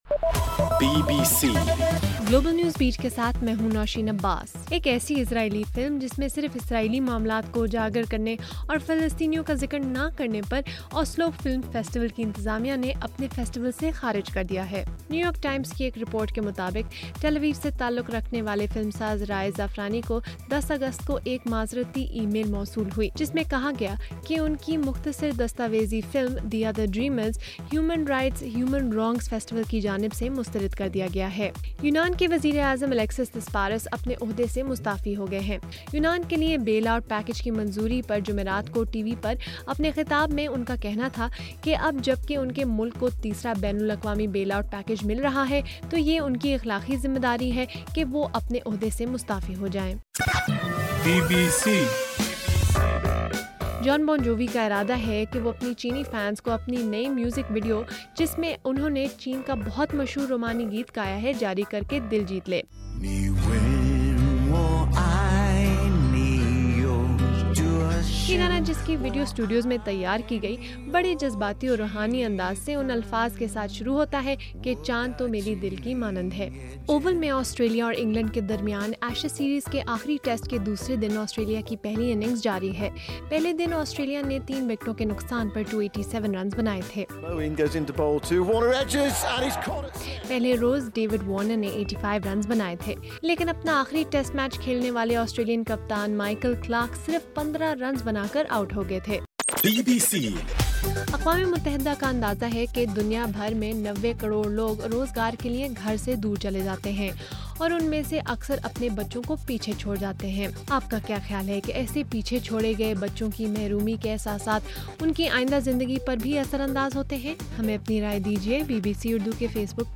اگست 21: رات 10 بجے کا گلوبل نیوز بیٹ بُلیٹن